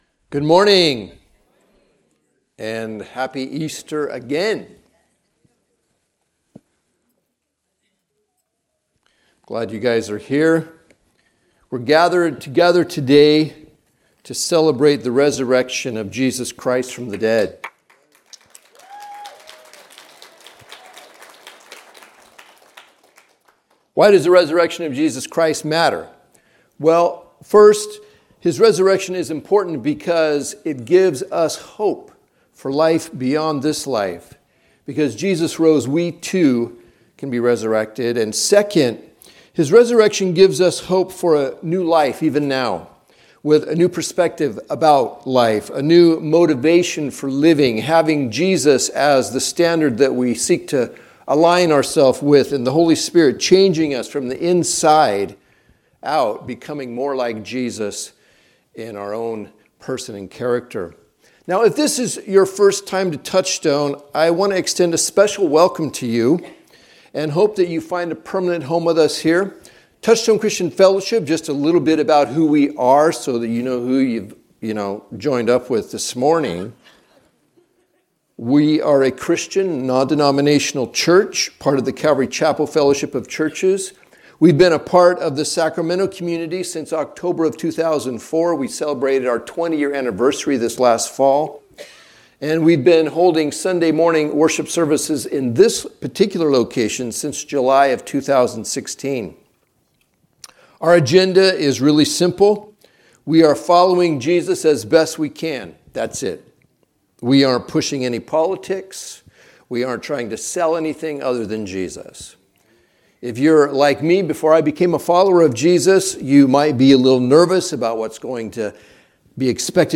It pointed to Jesus Christ, the true temple, God with us. Topics: Solomon, temple, tabernacle, worship Podcast We also offer the Sunday morning teachings in the form of an audio podcast.